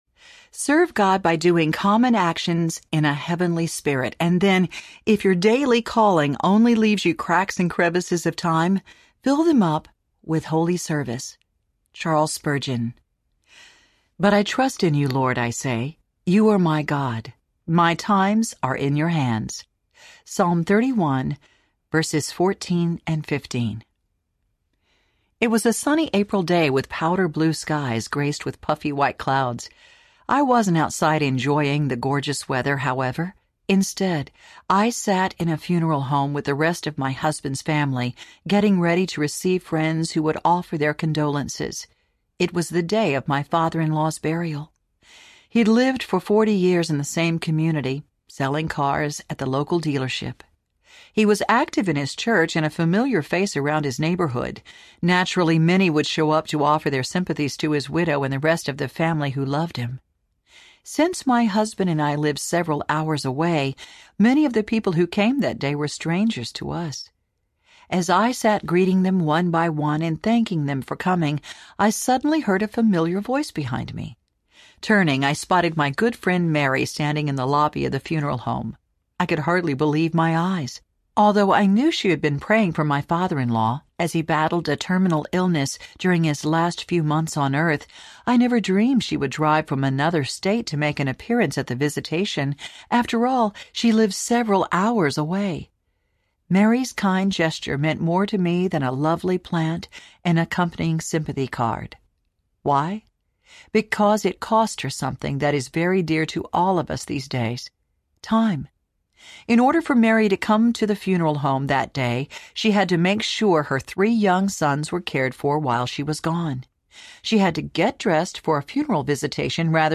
Listen, Love, Repeat Audiobook
Narrator